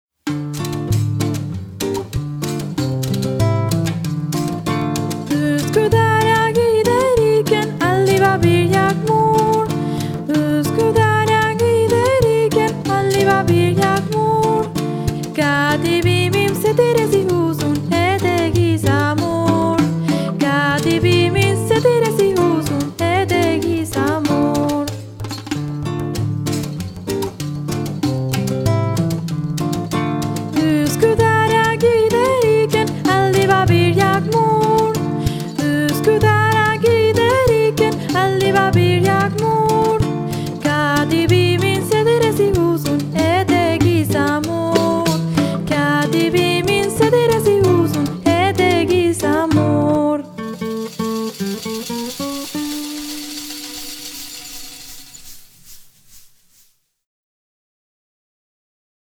Canción turca para danzar con pañuelos de colores